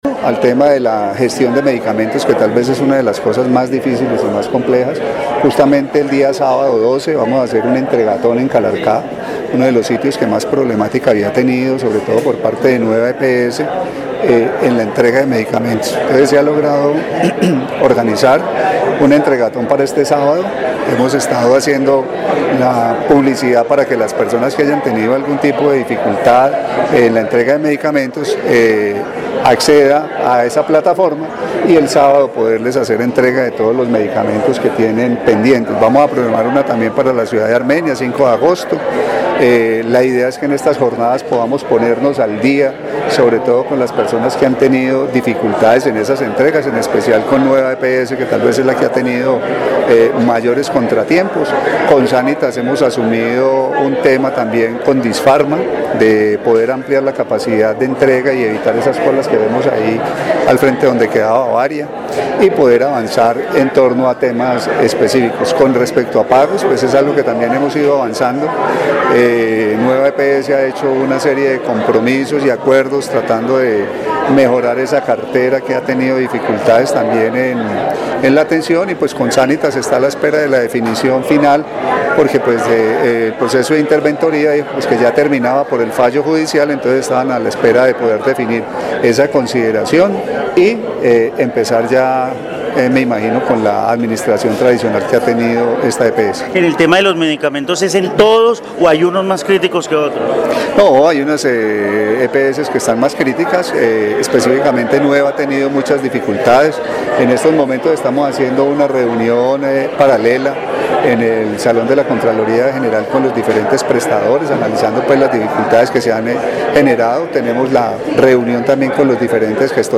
Carlos Alberto Gómez, secretario de salud del Quindío